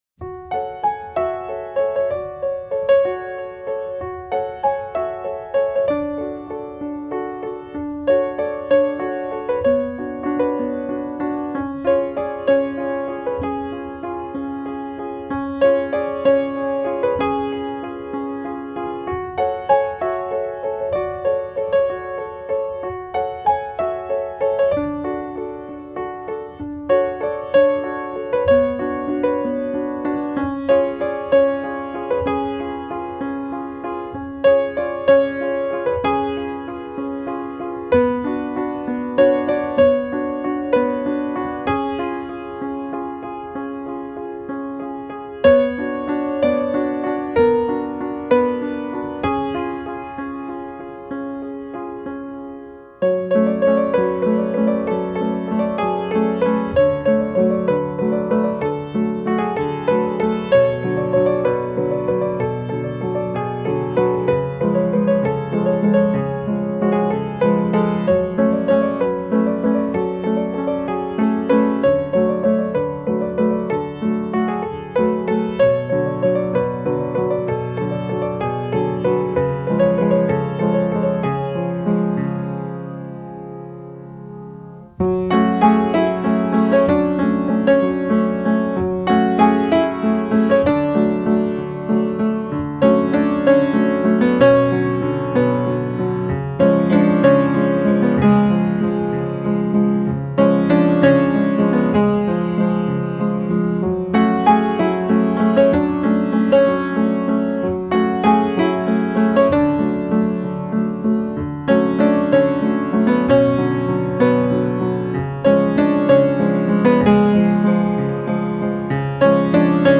Classical, Neo-Classical